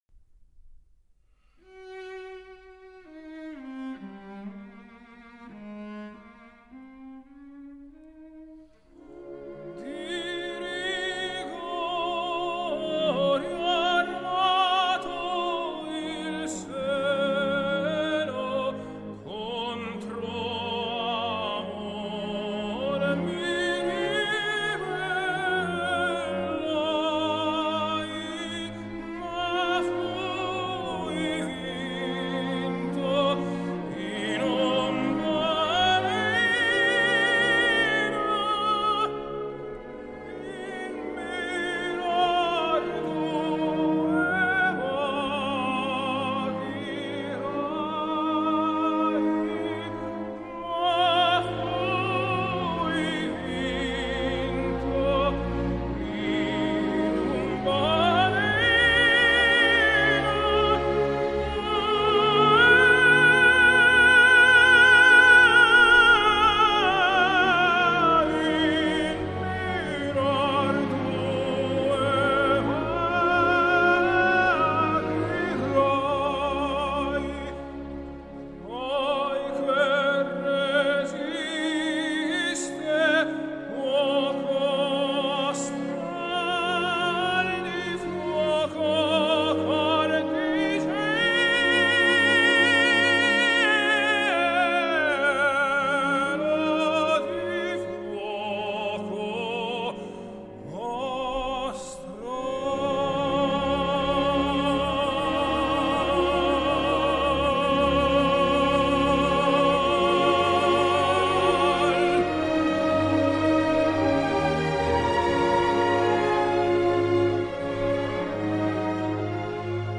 类别：古典音乐